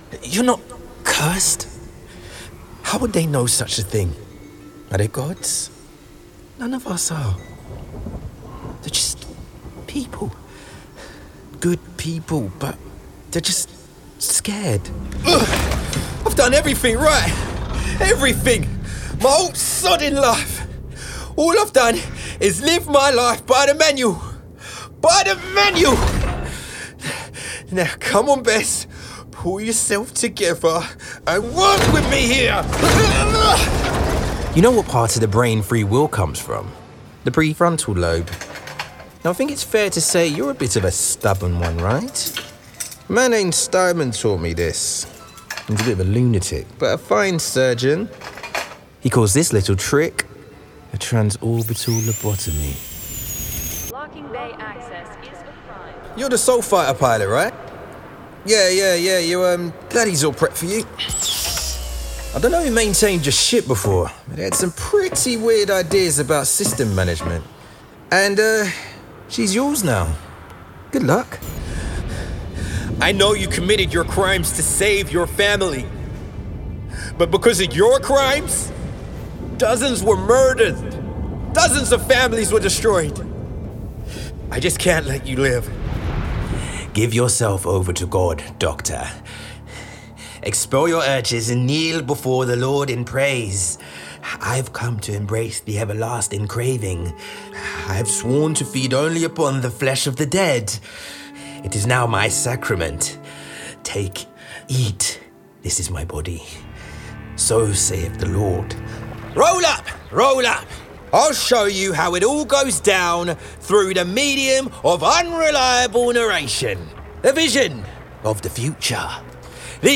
Gaming Showreel
Male
Multicultural London English (MLE)
Neutral British
Confident
Cool
Reassuring